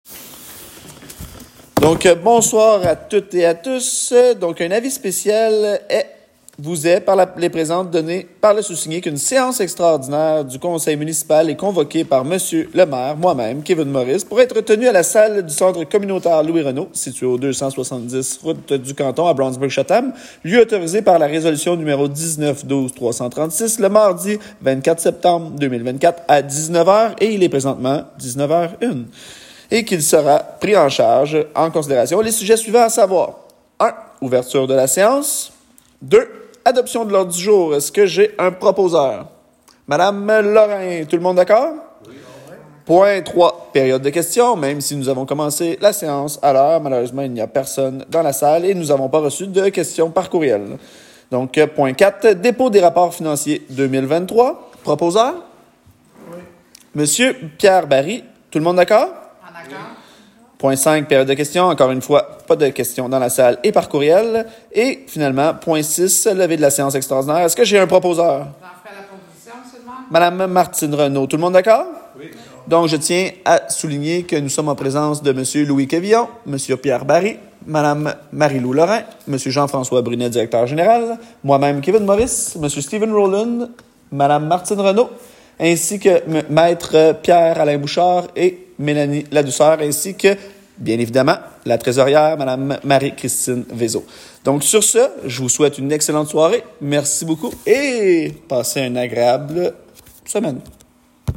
Séances du conseil
24 septembre 2024Séance extraordinaire